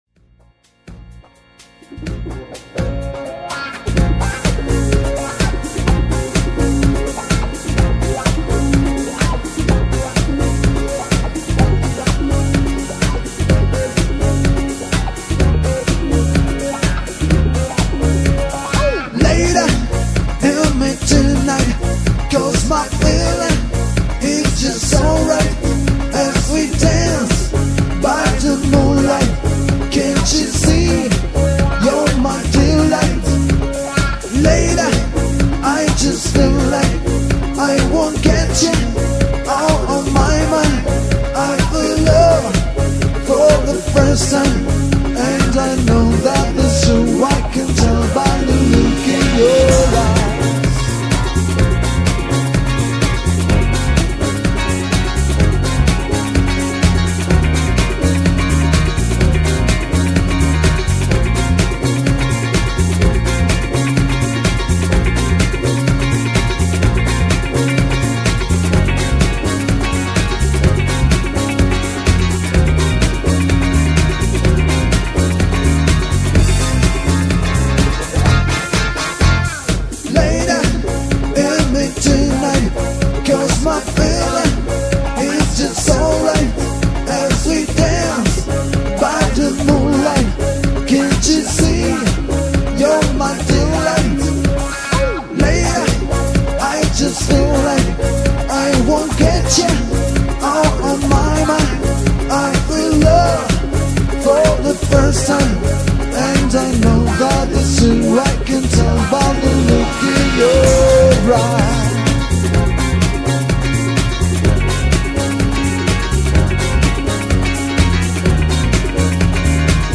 Wedding receptions entertainment and dance party music:
Pop, Rock, soul, dance - 2 Guitar 2 voices